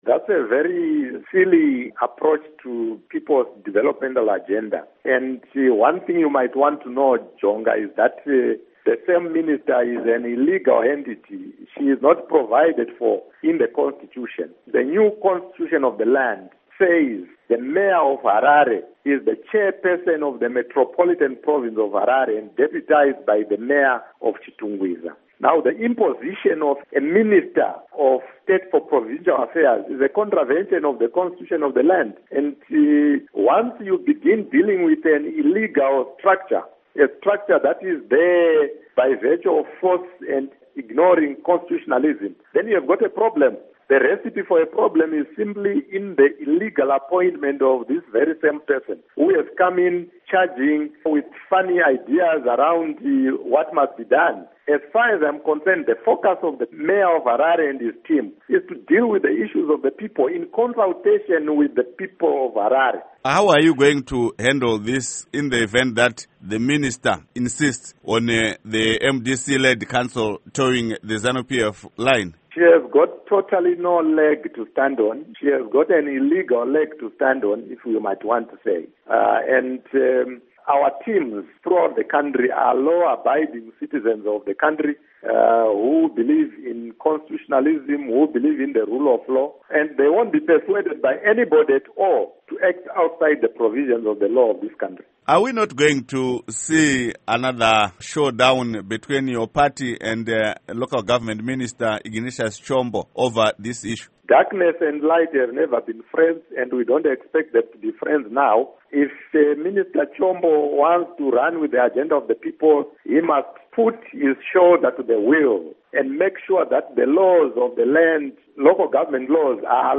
Interview with Sesel Zvidzai